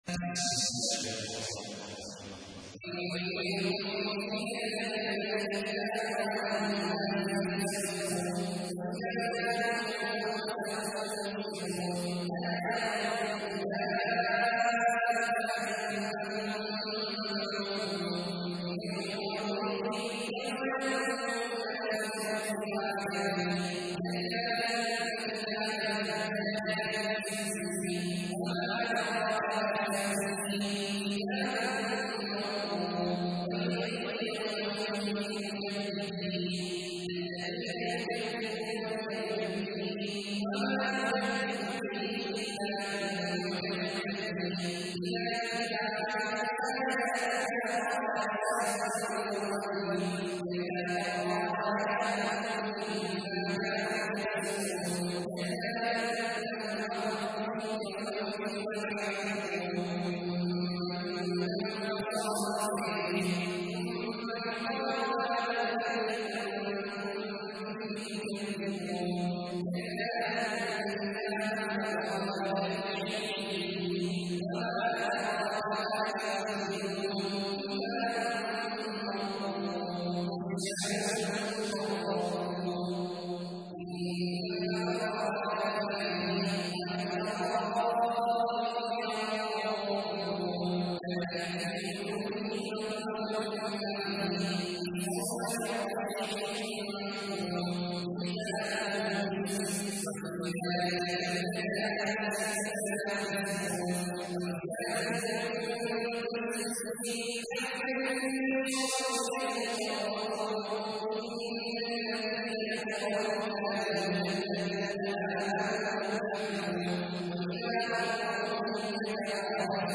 تحميل : 83. سورة المطففين / القارئ عبد الله عواد الجهني / القرآن الكريم / موقع يا حسين